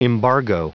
Prononciation du mot embargo en anglais (fichier audio)
Prononciation du mot : embargo